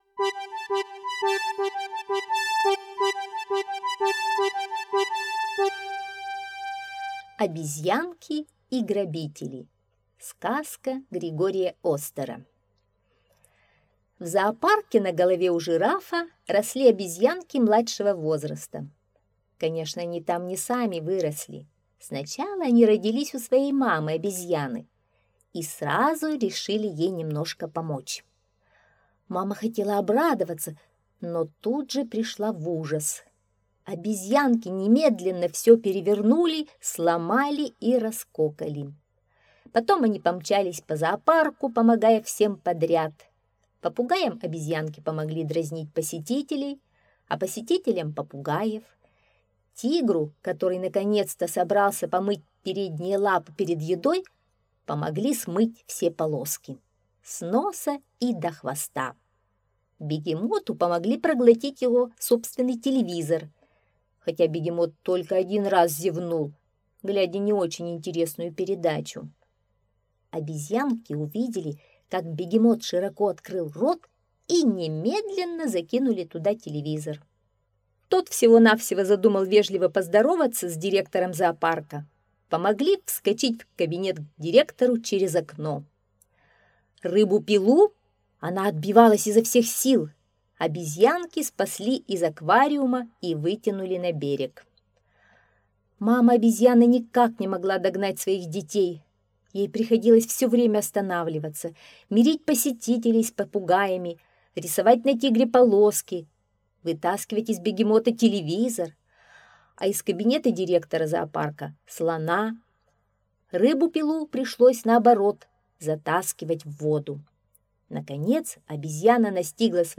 Обезьянки и грабители — аудиосказка Григория Остера.